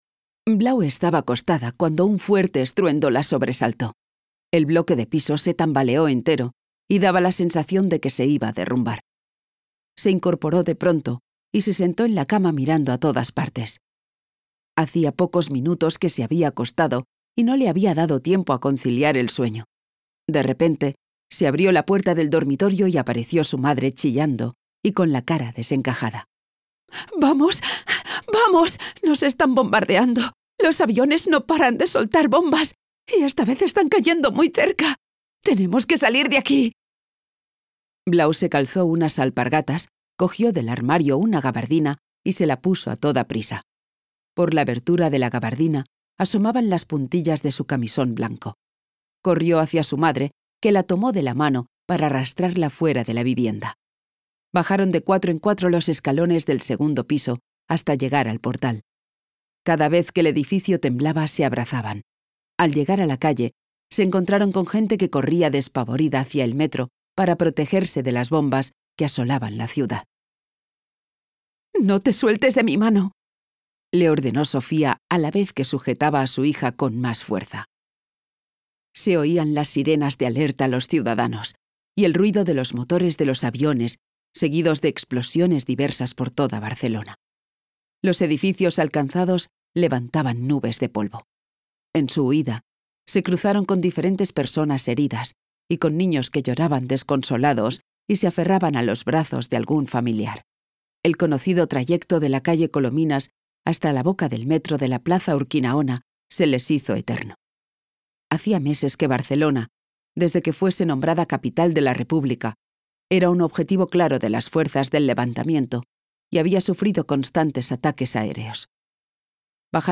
Audiolibro María del Blau